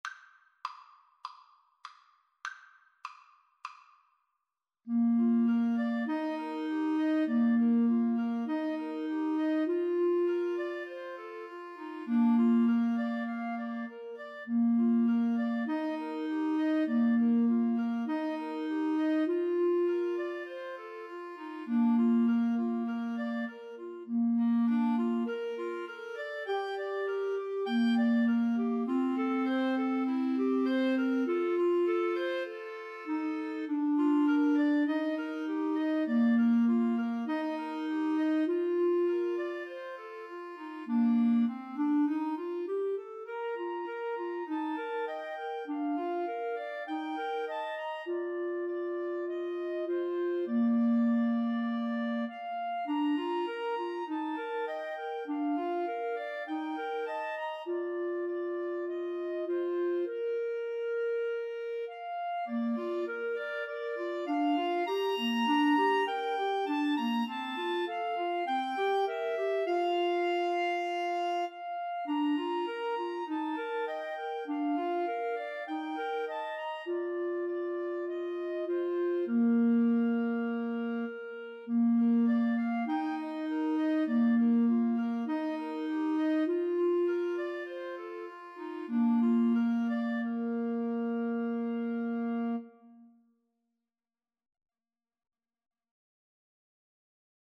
4/4 (View more 4/4 Music)
Andante